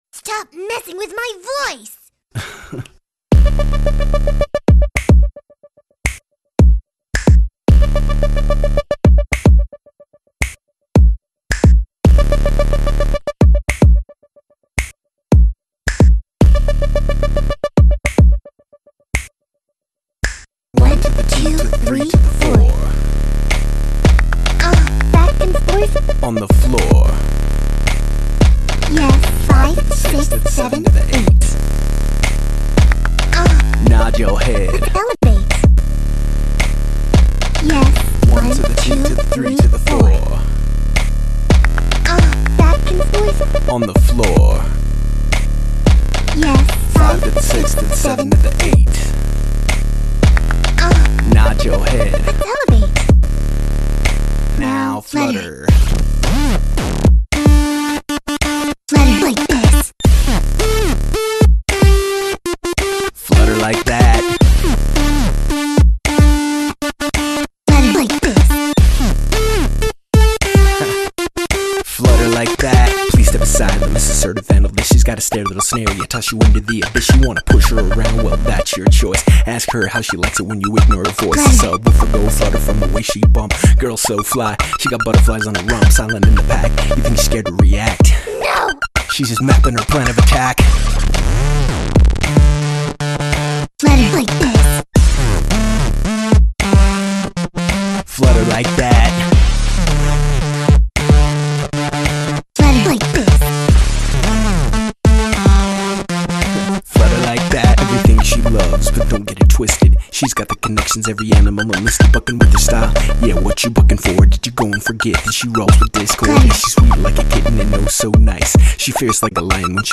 groovy, sassy